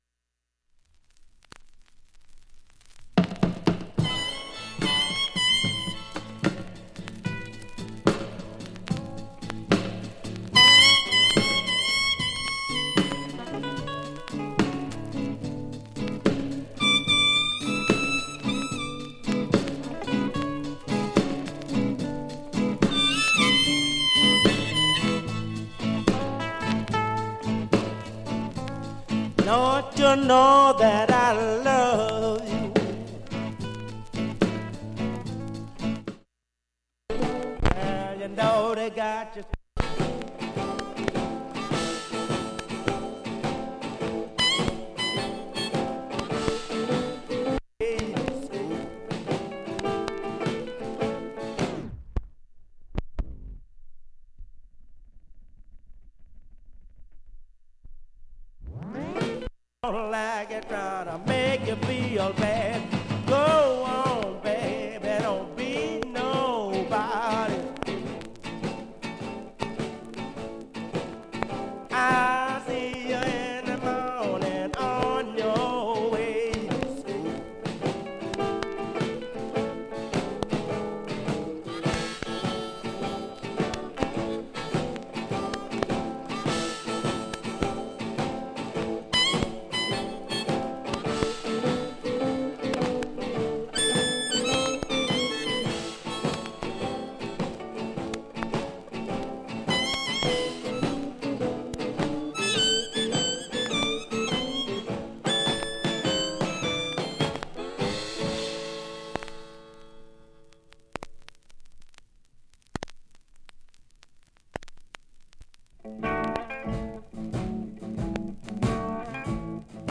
普通にいい音質です。
55秒の間に周回プツプツ出ます。
45秒の間に周回プツプツ出ます。
現物の試聴（上記録音時間(3m54s）できます。音質目安にどうぞ
◆ＵＳＡ盤 Repress, Mono